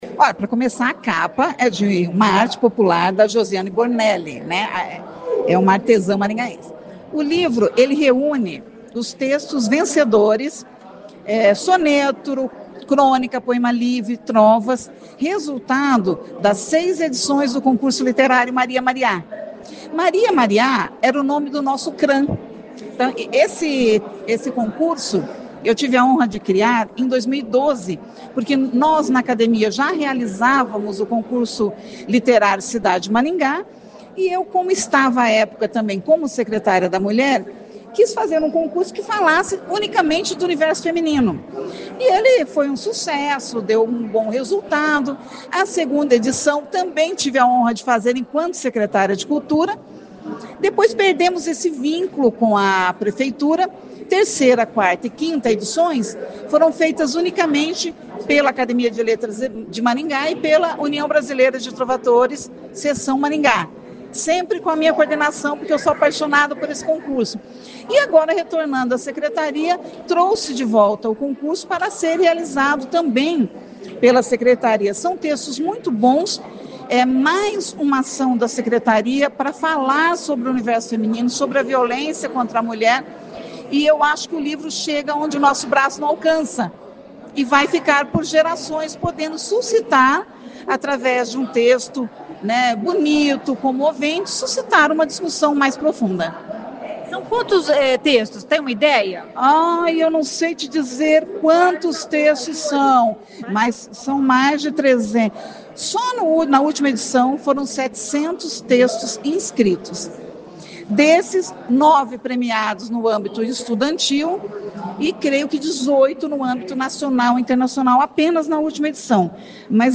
Ouça o que diz a secretária Olga Agulhon: